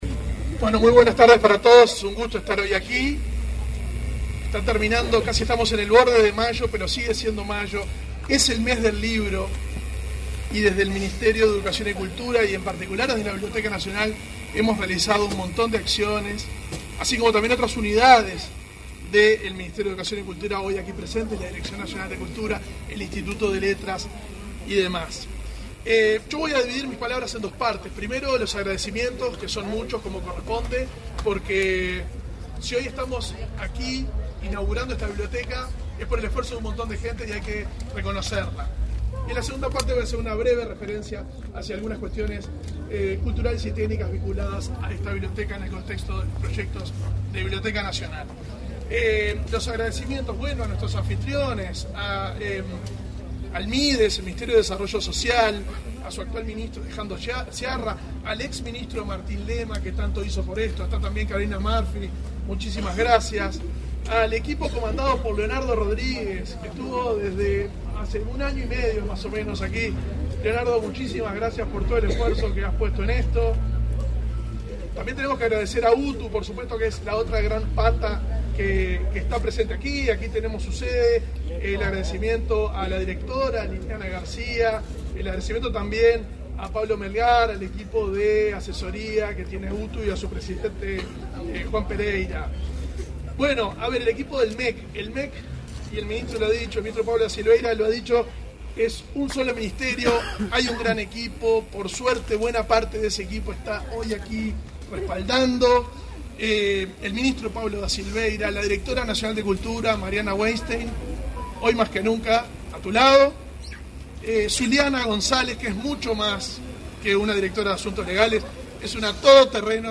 Acto de inauguración de la biblioteca en el Centro Aparicio Saravia
Acto de inauguración de la biblioteca en el Centro Aparicio Saravia 30/05/2024 Compartir Facebook X Copiar enlace WhatsApp LinkedIn El Ministerio de Educación y Cultura (MEC) inauguró, este 30 de mayo, una biblioteca en el Espacio MEC del Cento de Rerefencia de Políticas Sociales Aparicio Saravia, que será gestionada por la Biblioteca Nacional de Uruguay (BNU). Participaron en el evento el ministro del MEC, Pablo da Silveira; el ministro de Desarrollo Social, Alejandro Sciarra, y el director de la BNU, Valentín Trujillo.